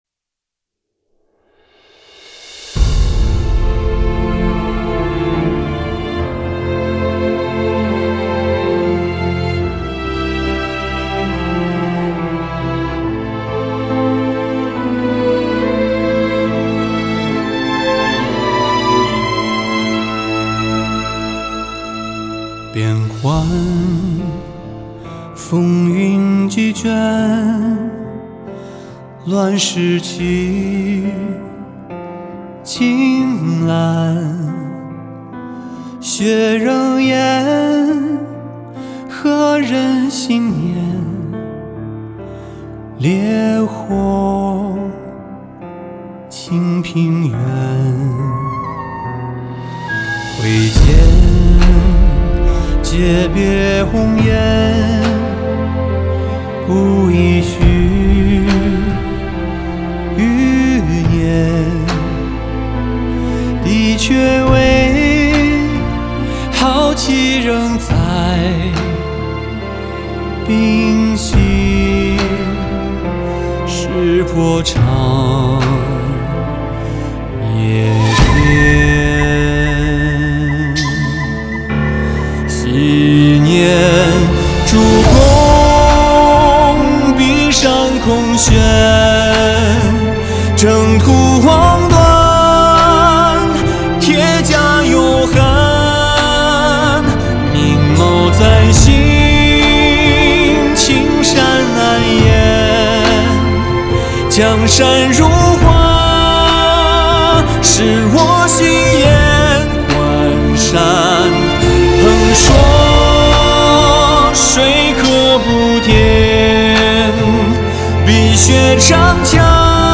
2016年度最佳、最流行、最好听的流行歌曲。
透明的音色脱俗的质感真抵灵魂。